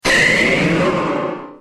Cri de Méga-Florizarre dans Pokémon X et Y.